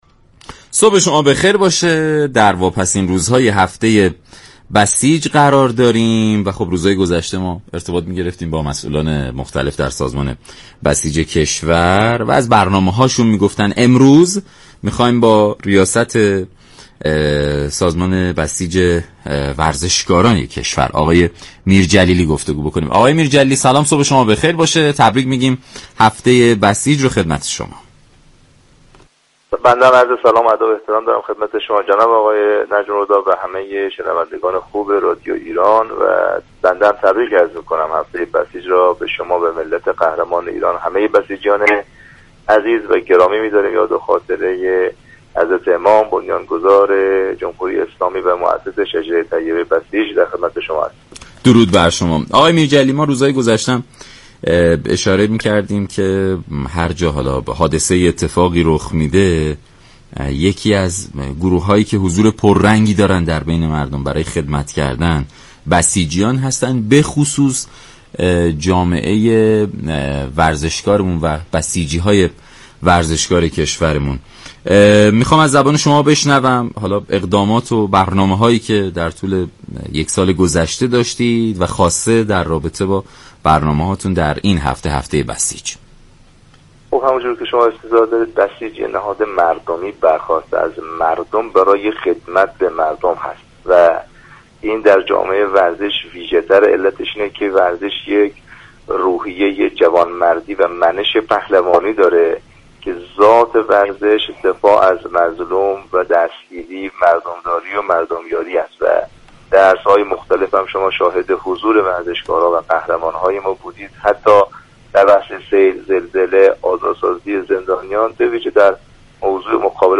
به گزارش شبكه رادیویی ایران، «مهدی میرجلیلی» رییس سازمان بسیج ورزشكاران كشور در برنامه «سلام صبح بخیر» در پاسخ به این پرسش كه سازمان بسیج ورزشكاران كشور طی یكسال گذشته چه اقداماتی را انجام داده است؟ گفت: بسیج ورزشكاران كشور در رخدادها و اتفاقات مختلف نظیر سیل، زلزله، شیوع ویروس كرونا همواره كنار مردم بوده اند و كمك زیادی را به آنان رسانیده اند.